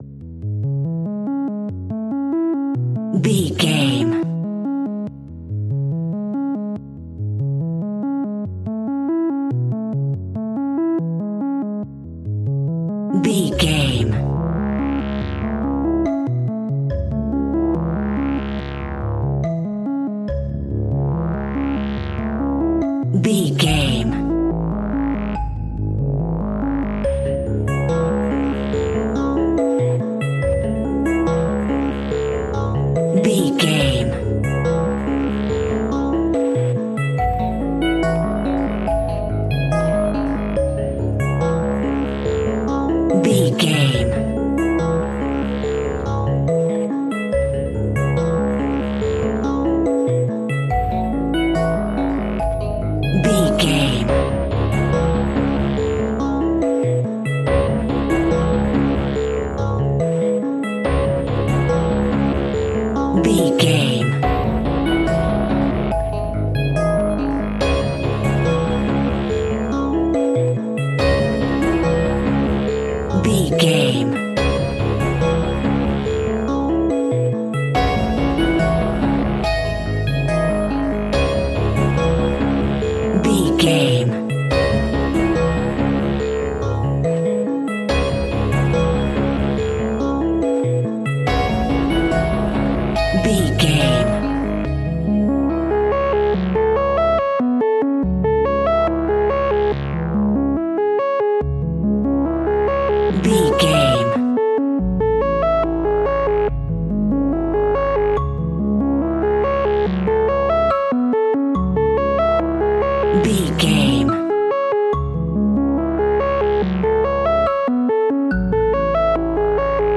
A modern and futuristic style horror and suspense track.
Aeolian/Minor
D♭
piano
synthesiser